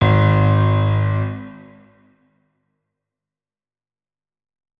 piano
notes-08.ogg